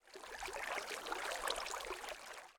1.21.4 / assets / minecraft / sounds / liquid / water.ogg
water.ogg